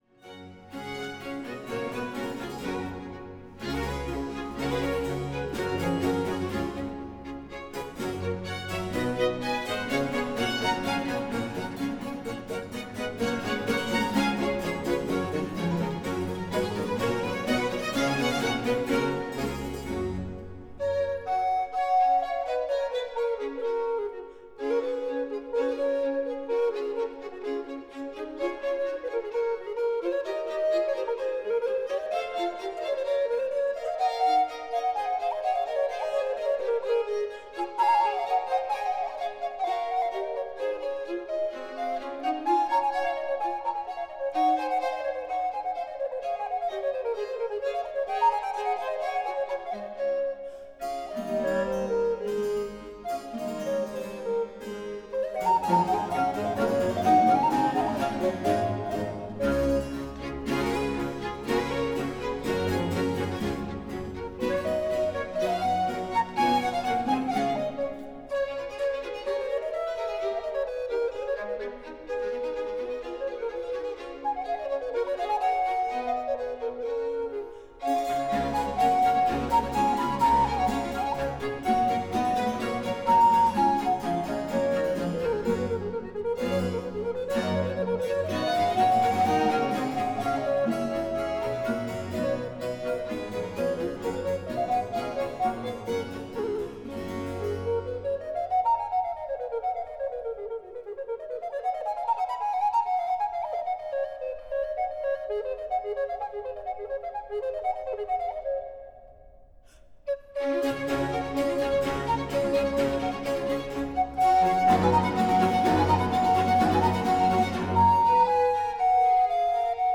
CONCERTO IN G MAJOR FOR FLUTE AND ORCHESTRA
ALLEGRO